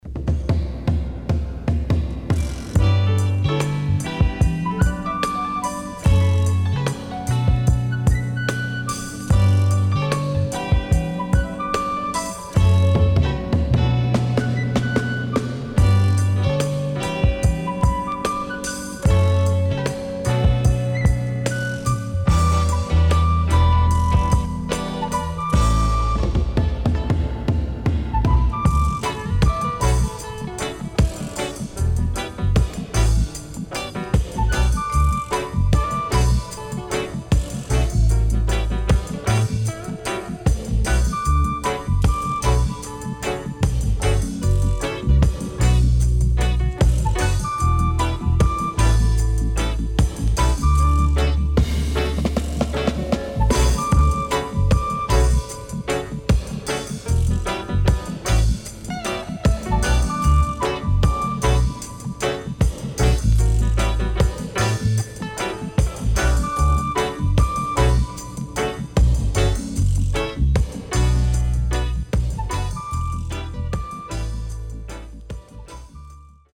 Sweet Female Vocal & Dubwise
SIDE A:うすいこまかい傷ありますがノイズあまり目立ちません。